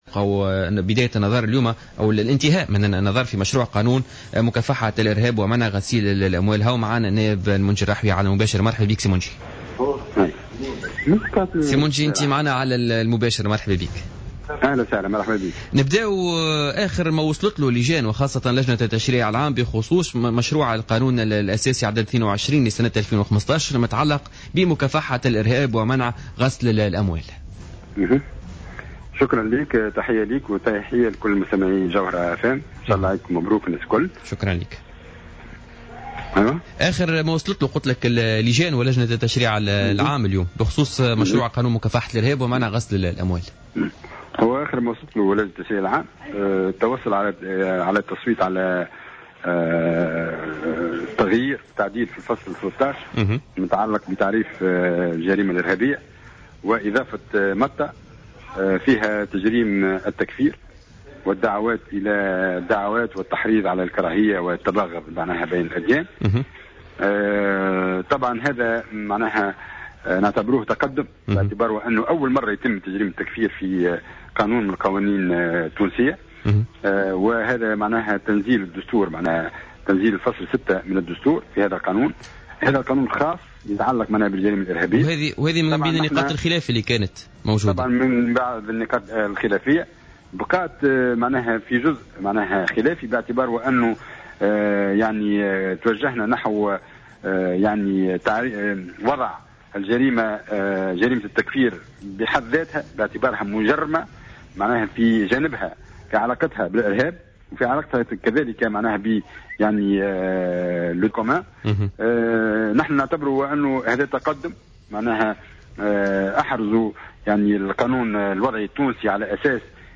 وأكد في تصريح لـ"جوهرة أف أم" في برنامج "بوليتيكا" أنه تم تعديل الفصل 13 من مشروع قانون الإرهاب ليصبح التكفير والدعوة إلى الكراهية والتباغض بين الأديان جريمة إرهابية أيضا تقتضي نفس عقوبات الجرائم الإرهابية.